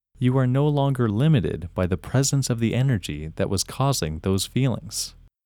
IN – First Way – English Male 28
IN-1-English-Male-28.mp3